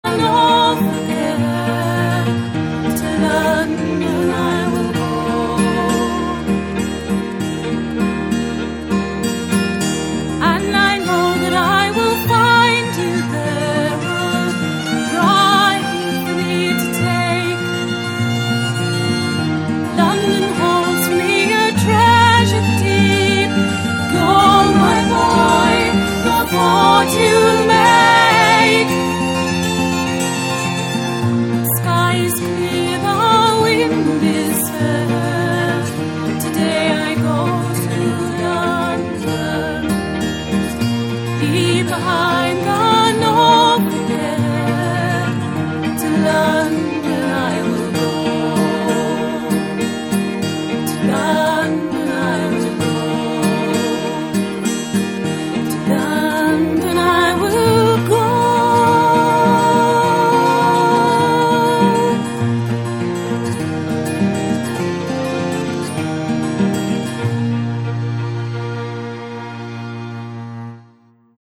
What does it sound like? Great! for Irish or English dance music, with a strong A and stronger E string, with a good ‘bite' to the sound using hard bowing.
This fiddle is good enough to use on a recording, so it was used in a set of songs for a charity concert we put onto CD just before Christmas ‘07.
‘Horace goes to London' with this fiddle doing both the rhythm bowing in the background and the ‘descant' over the voice.